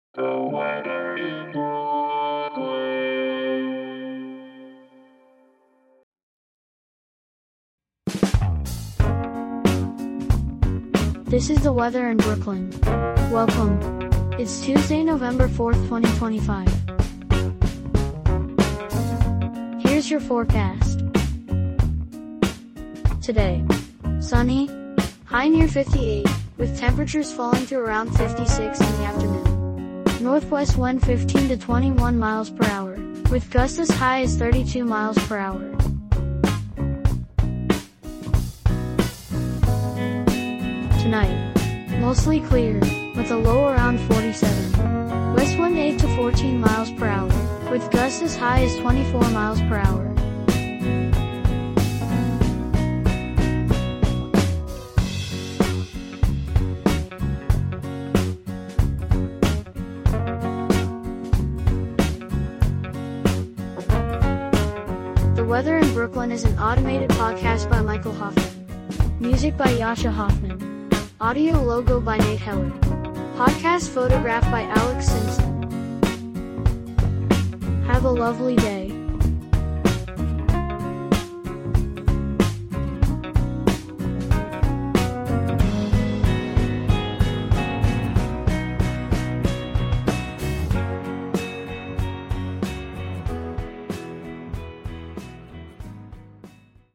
An automated podcast bringing you your daily weather forecast for Brooklyn, NY.